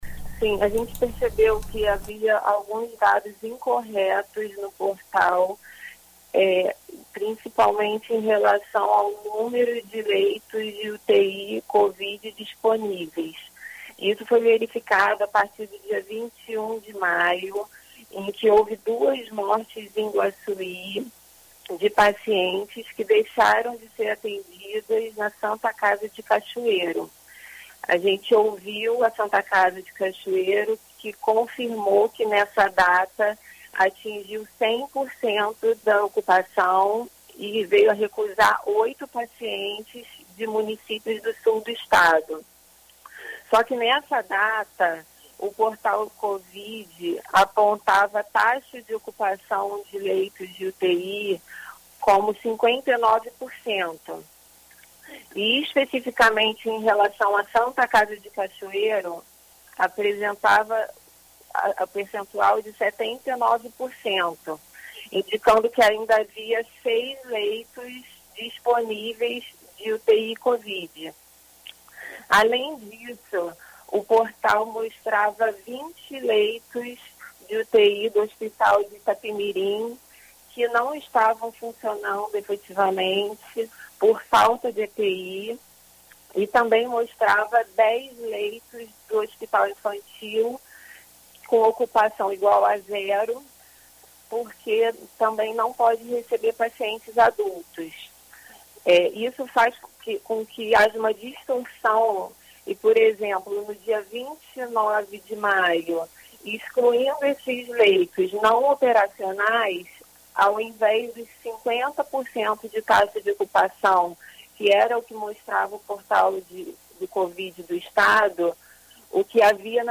Em entrevista à BandNews FM Espírito Santo, a procuradora da República em Cachoeiro de Itapemirim, Renata Maia Albani explicou as investigações do MPF e os motivos para a ação.